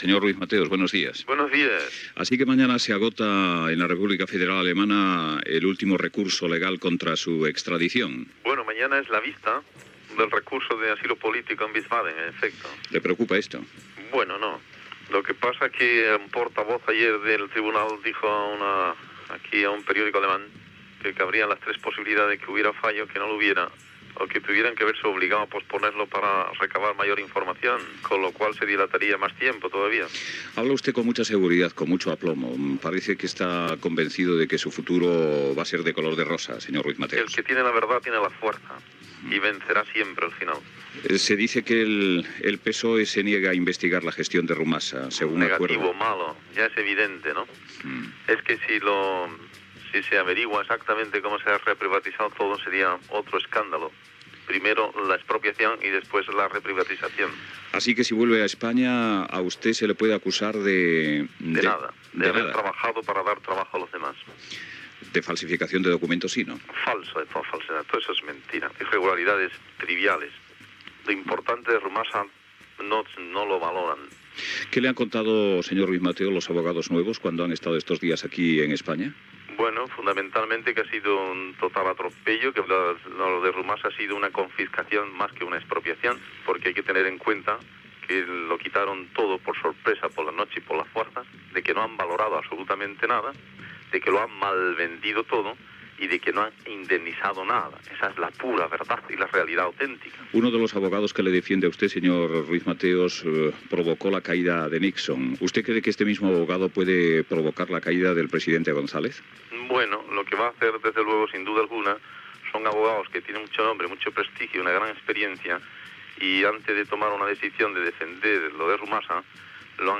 Fragment d'una entrevista a José Maria Ruiz Mateos, president de l'expropiada empresa Rumasa
Info-entreteniment